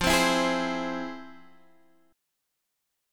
FmM7 chord